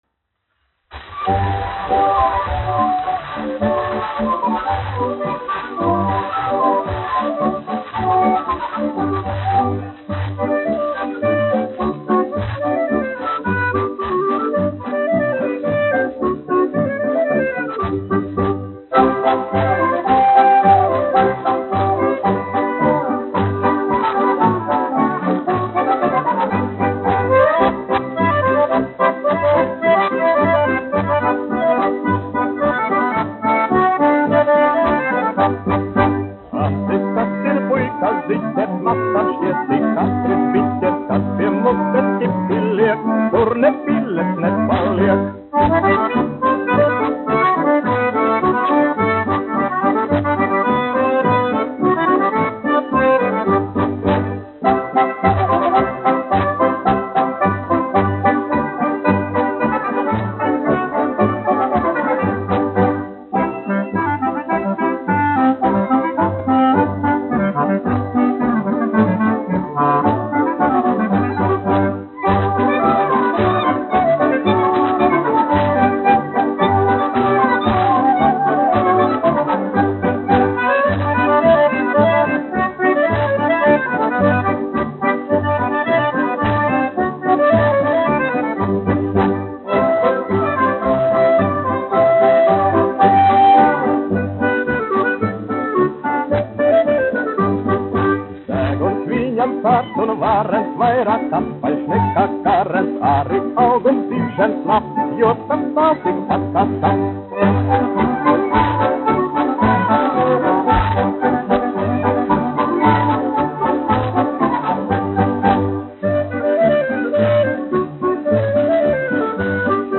1 skpl. : analogs, 78 apgr/min, mono ; 25 cm
Polkas
Populārā mūzika
Skaņuplate
Latvijas vēsturiskie šellaka skaņuplašu ieraksti (Kolekcija)